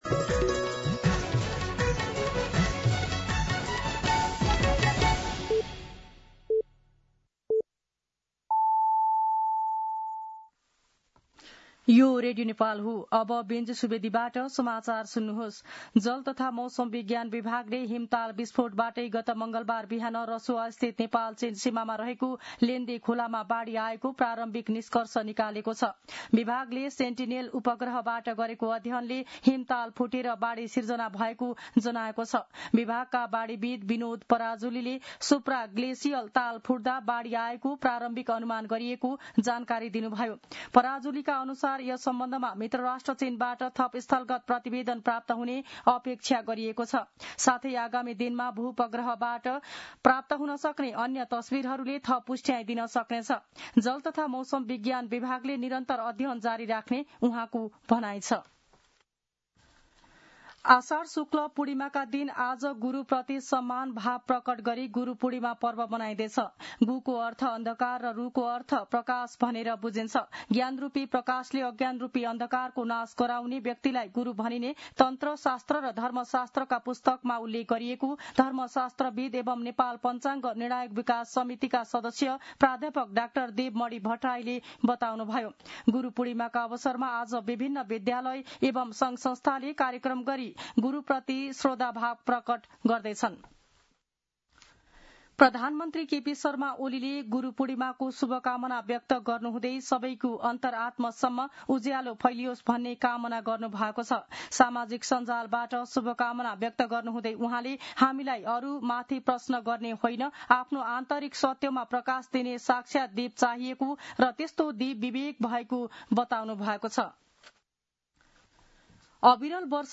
An online outlet of Nepal's national radio broadcaster
दिउँसो १ बजेको नेपाली समाचार : २६ असार , २०८२